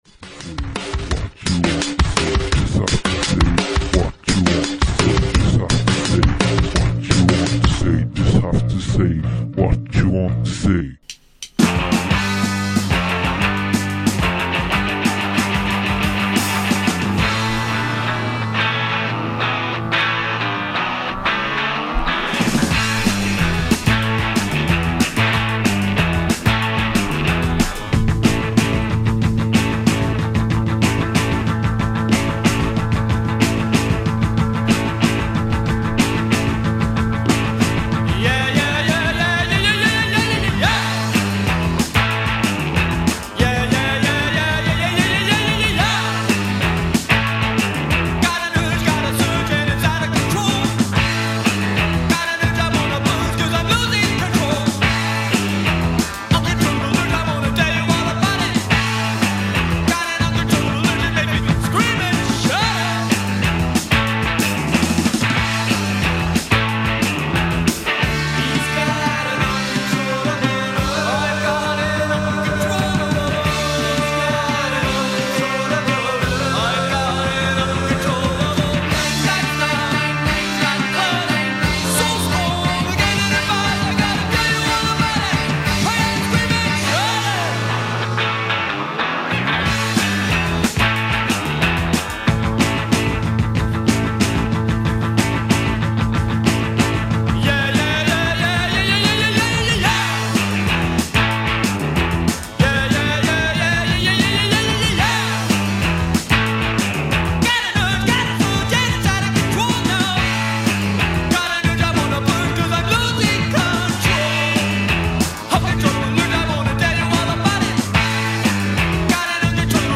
Extrait de l'Emission "Zack and Judy" sur Radio Saint Ferréol